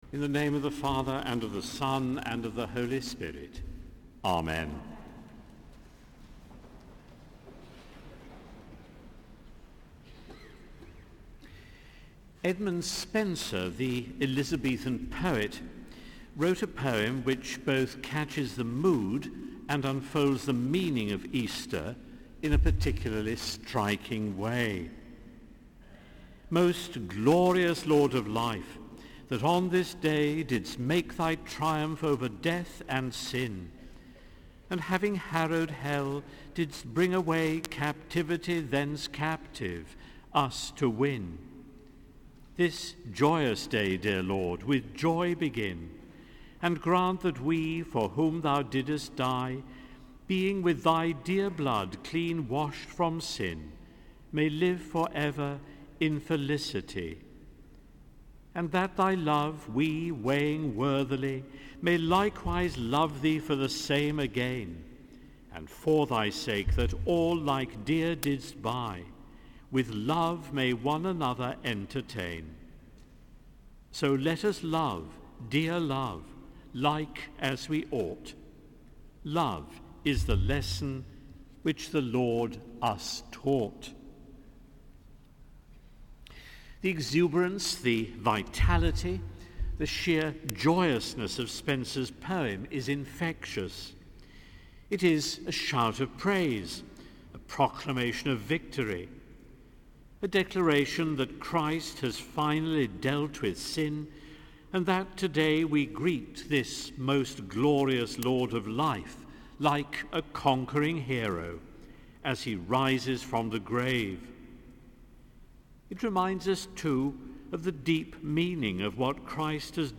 Sermon: Easter Day Evensong 2015